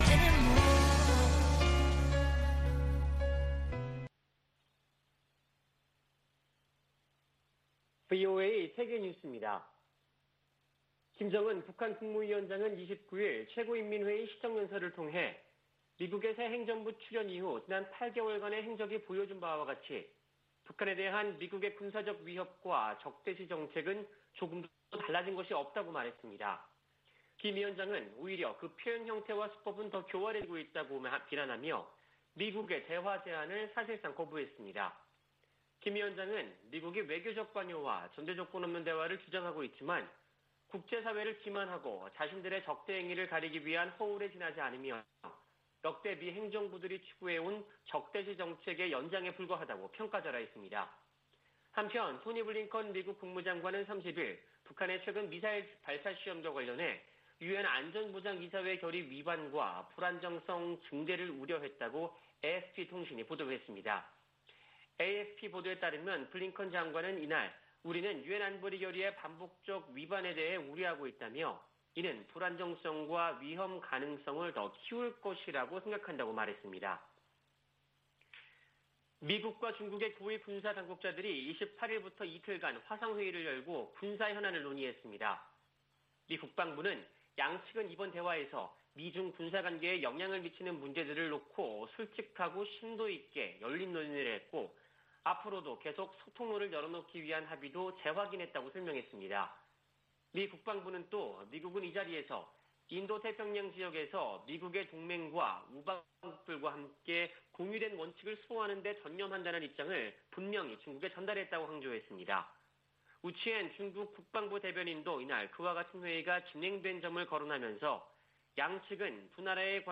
VOA 한국어 아침 뉴스 프로그램 '워싱턴 뉴스 광장' 2021년 9월 26일 방송입니다. 김정은 북한 국무위원장은 조 바이든 행정부에서도 미국의 대북 적대시 정책이 변한 게 없다며 조건 없는 대화 재개를 거부했습니다. 미 국무부는 북한에 적대적 의도가 없다고 거듭 강조했습니다.